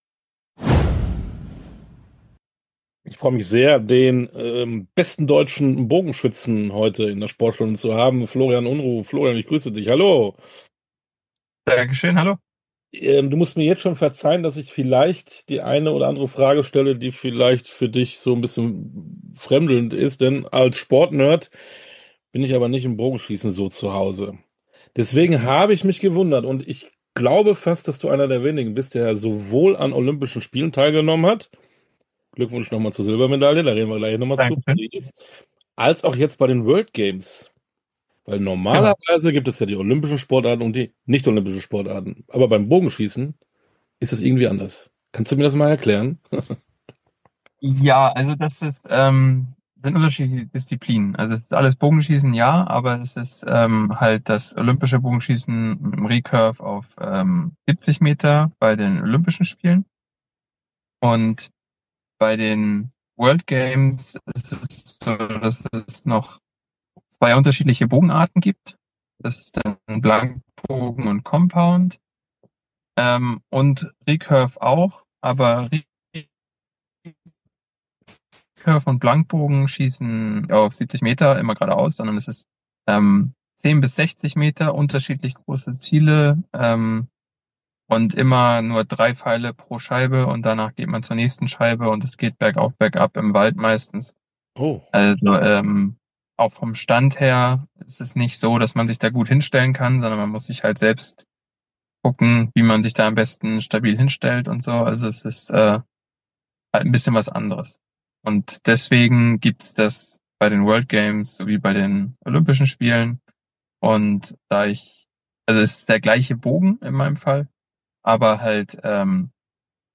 Tauchen Sie ab in die Sportstunde ungeungeschnittentinterviews in ihrer authentischen, ungeschnittenen Langfassung. Diese tiefgründigen Sportstunde Interviews liefern Ihnen nicht nur Fakten, sondern auch einzigartige Einblicke in die Welt des Sports.